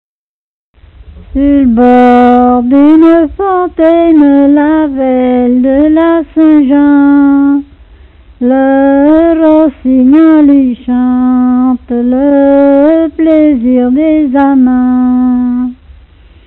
Mémoires et Patrimoines vivants - RaddO est une base de données d'archives iconographiques et sonores.
Répertoire de chansons populaires et traditionnelles
Pièce musicale inédite